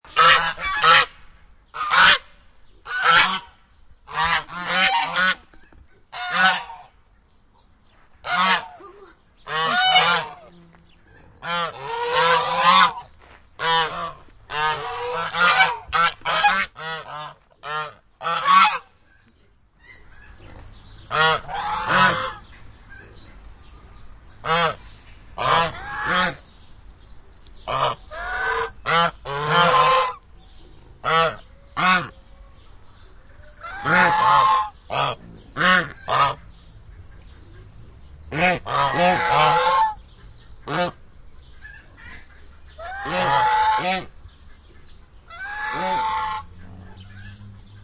Cackling_geese Bouton sonore